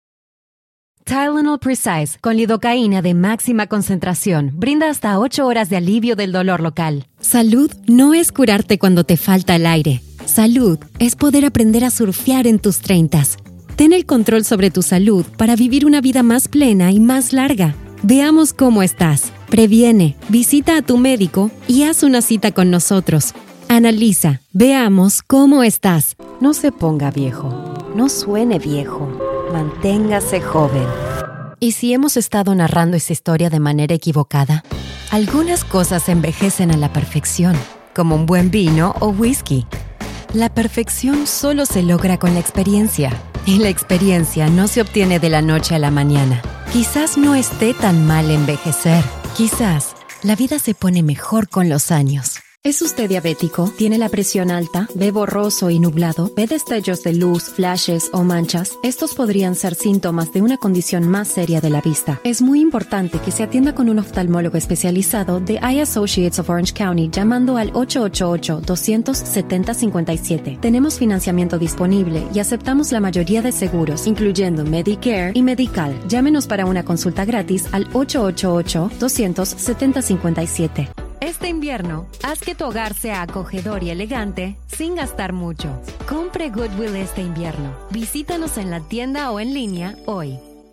Commercial demo in Latin American Spanish
DEMO VOZ Neutro commercial.wav